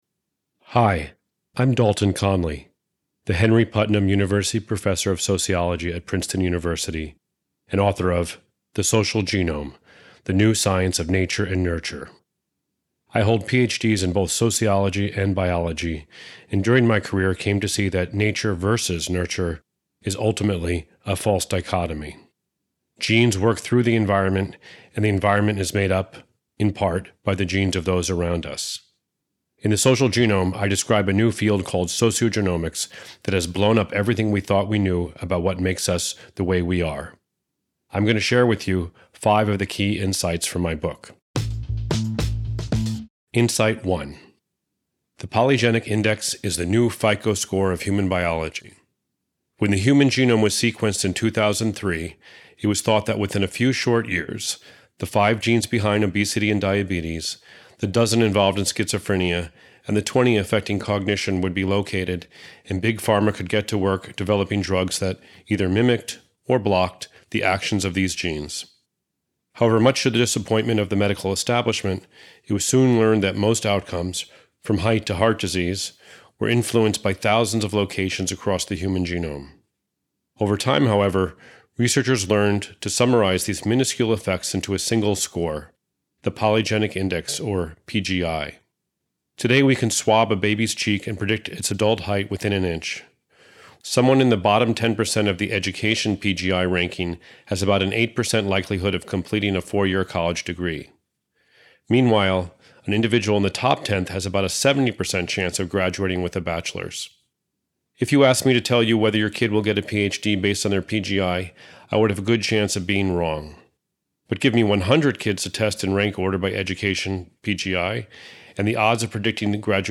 Listen to the audio version—read by Dalton himself—in the Next Big Idea App.